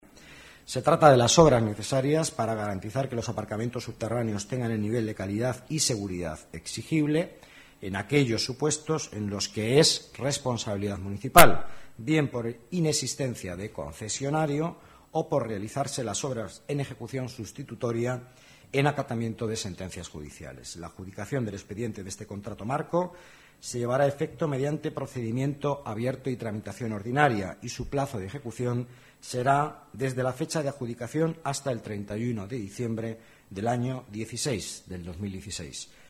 Nueva ventana:Declaraciones vicealcalde Madrid, Miguel Ángel Villanueva: obras de reforma para mejorar los aparcamientos públicos